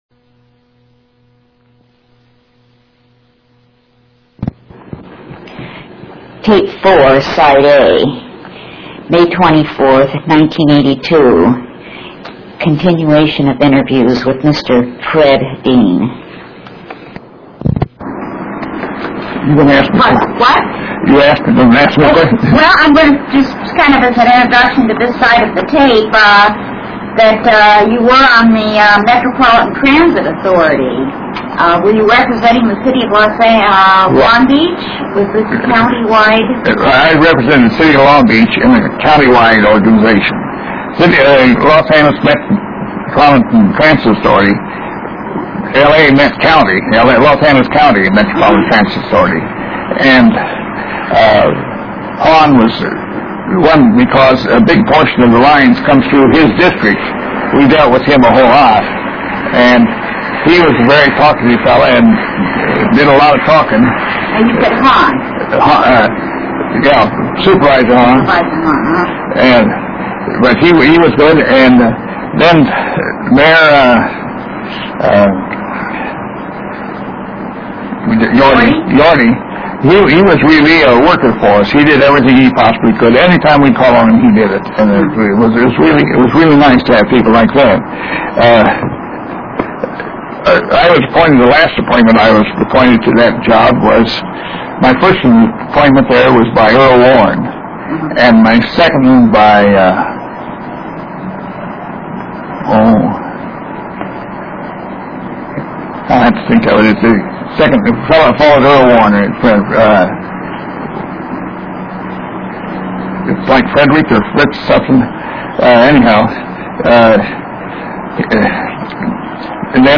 INTERVIEW DESCRIPTION - This is the fifth of six interviews was conducted in Dean's Long Beach home. 5/24/1982